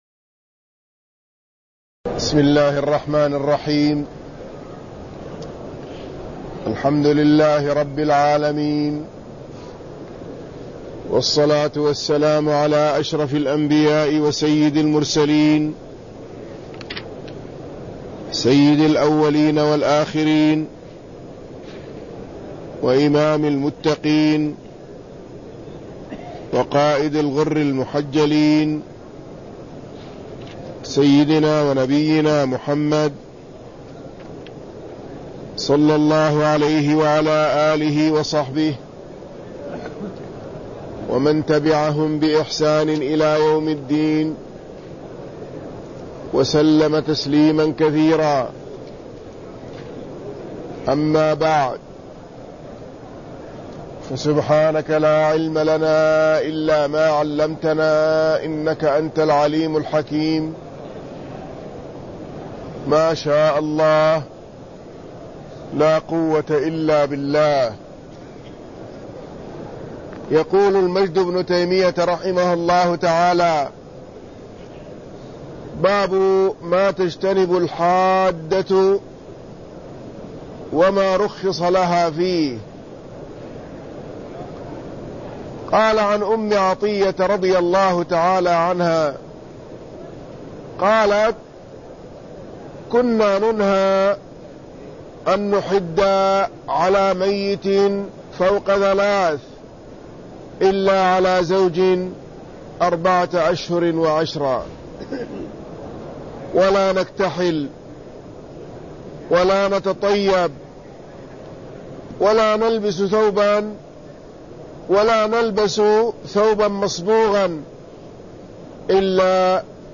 المكان: المسجد النبوي الشيخ